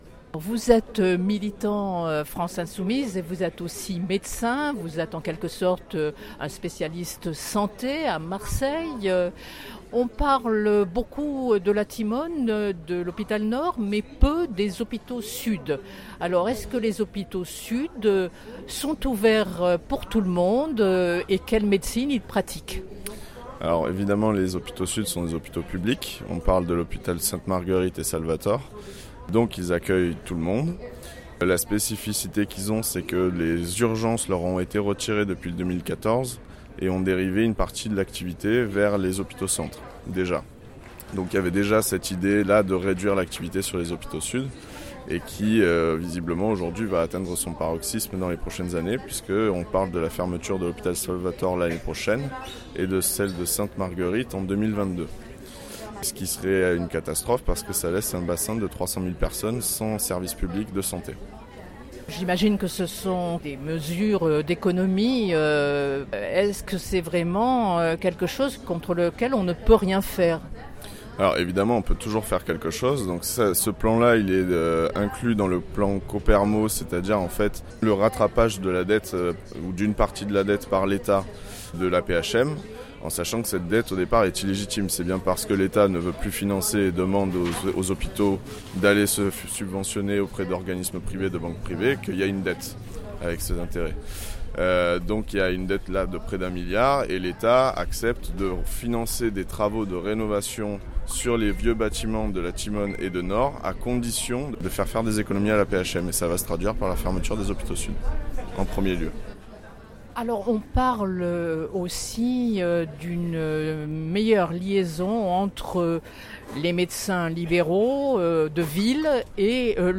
Les entretiens